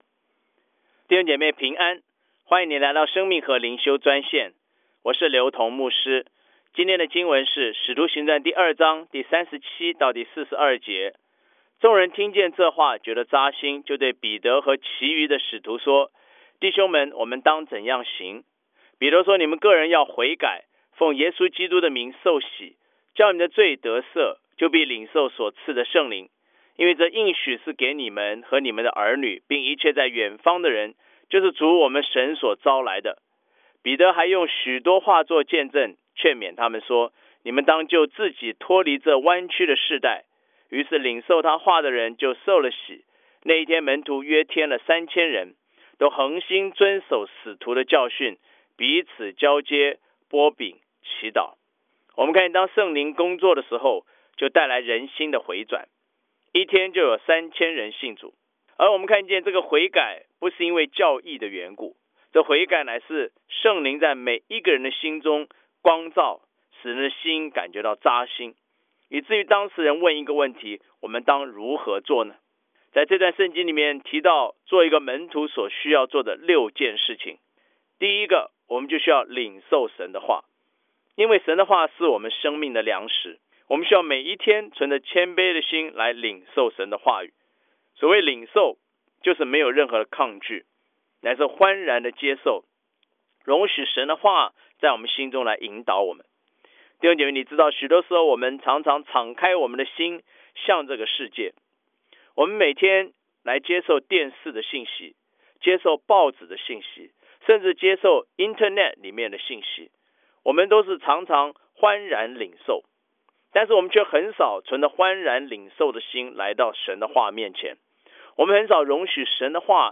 以生活化的口吻带领信徒逐章逐节读经